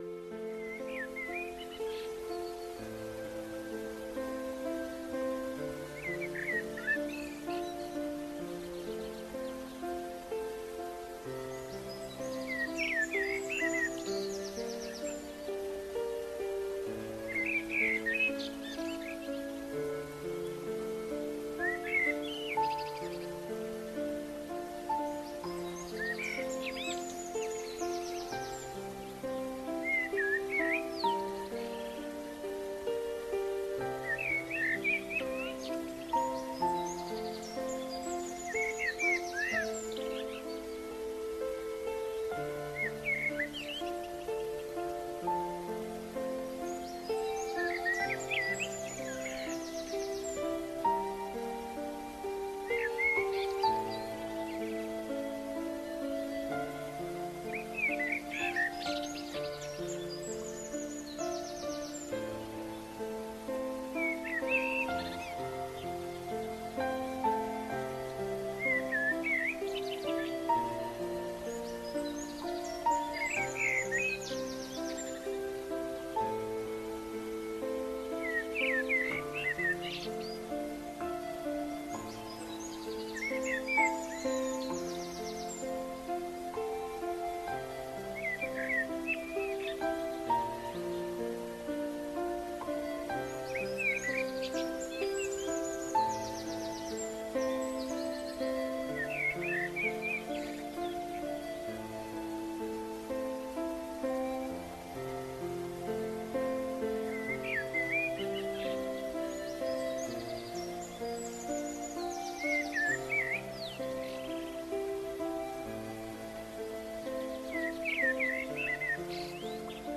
• Piano de cola con melodías suaves en escala pentatónica
• Grabación binaural con micrófonos Neumann KU 100
• Piano Steinway Modelo D grabado en estudio anecoico